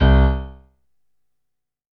55ay-pno01-c1.wav